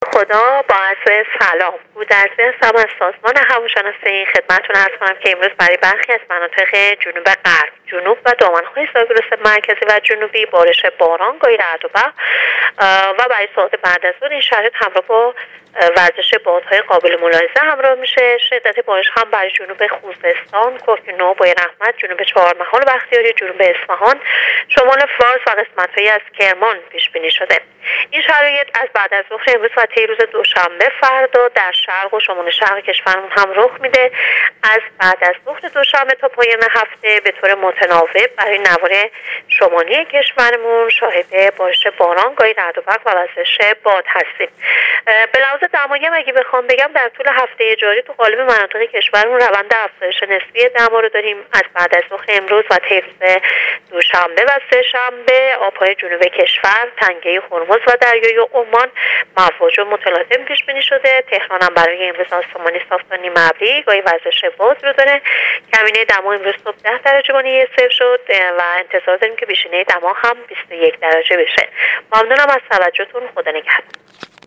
گزارش رادیو اینترنتی وزارت راه و شهرسازی از آخرین وضعیت آب و هوا هشتم اردیبهشت۹۸/بارش باران و وزش باد در نواحی جنوبی/ روند افزایش نسبی دما در اکثر نقاط کشور / آسمانی صاف تا نیمه‌ابری برای امروز تهران
کارشناس سازمان هواشناسی در گفت‌و‌گو با راديو اينترنتی پايگاه خبری وزارت راه و شهرسازی آخرين وضعيت هوا را تشریح کرد.